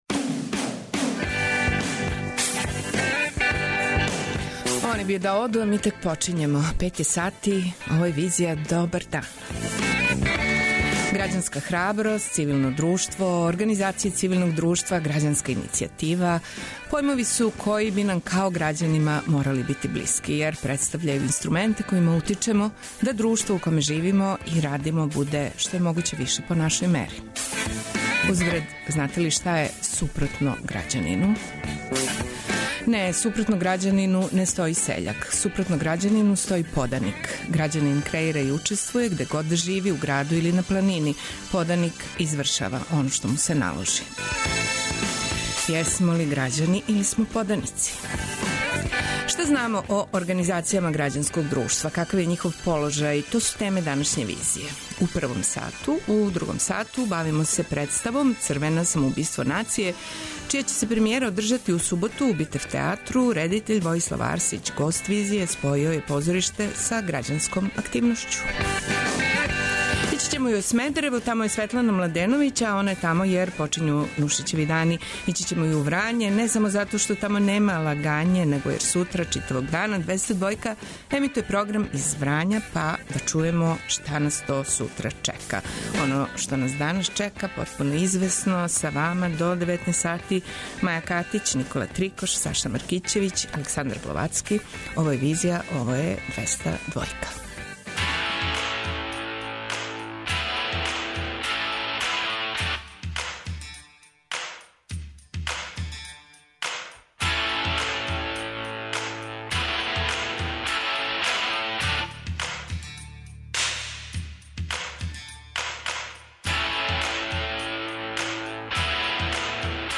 Београд 202 Социо-културолошки магазин, који прати савремене друштвене феномене.